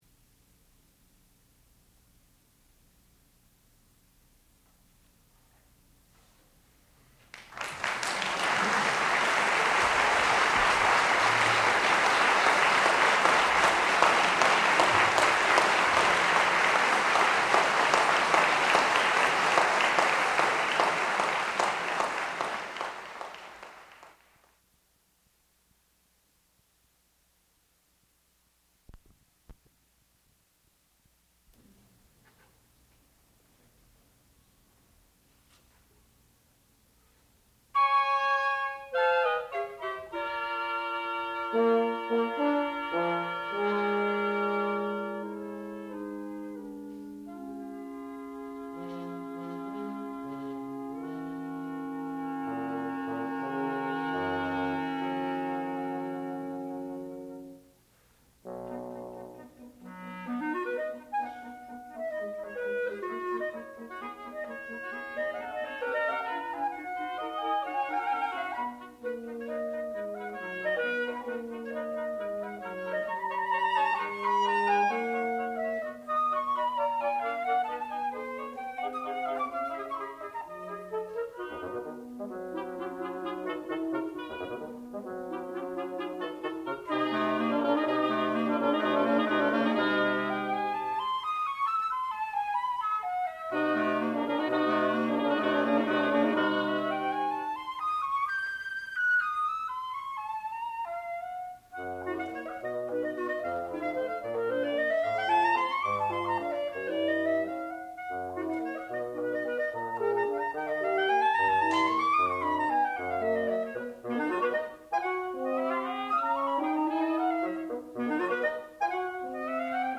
classical music
oboe
clarinet
flute